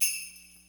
Perc [Tambourine].wav